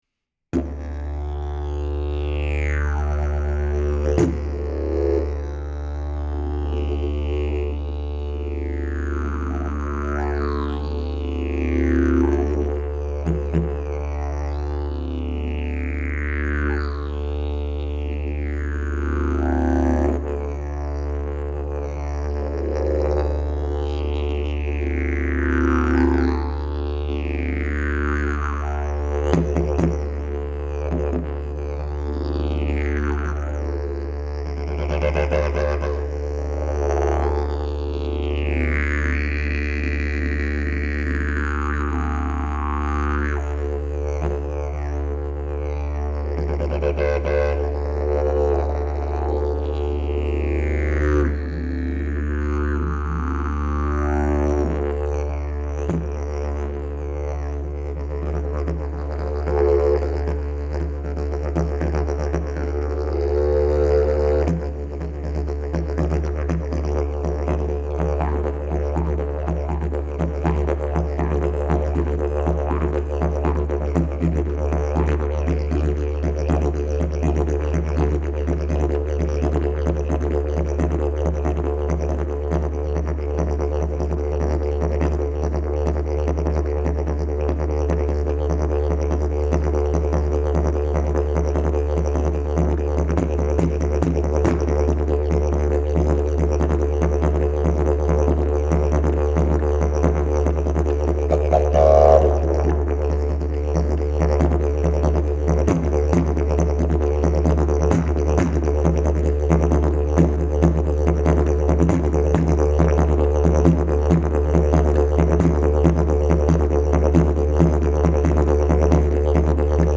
Key: D Length: 71" Bell: 8.25" Mouthpiece: Bloodwood, Cherry Back pressure: Very strong Weight: 5 lbs Skill level: Any
Didgeridoo #675 Key: D